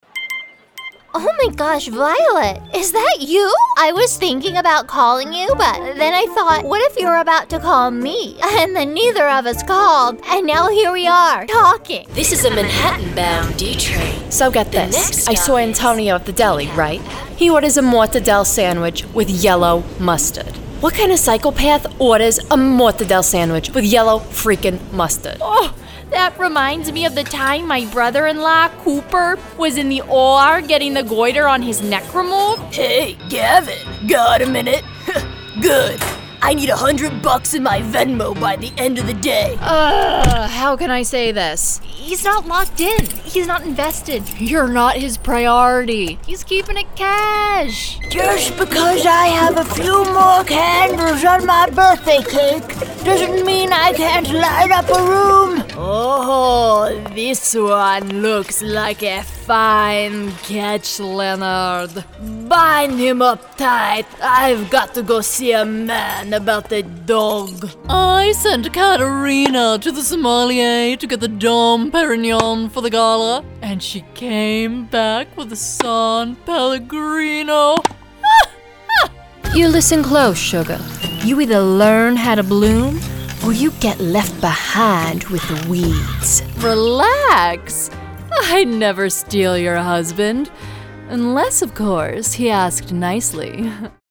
Demos
• Broadcast-quality home studio
• Microphone: Sennheiser MKE 600